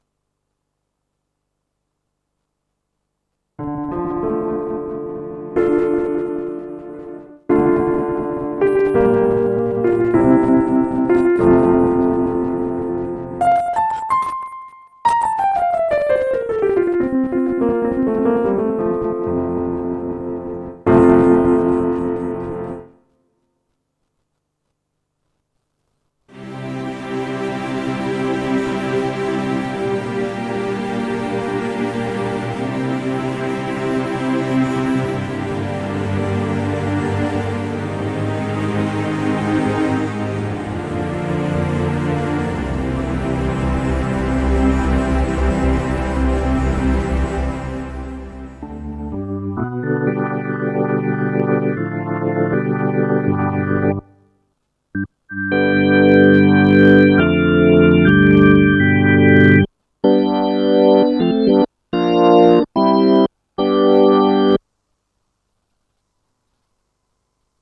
Die Audioausgabe klingt verzerrt inkl. "rhythmischer" Tonstörungen. Ich habe ein kurzes Audiofile angehängt, das den Effekt zeigt. Das Problem tritt auf allen Ausgängen auf (analog, digital).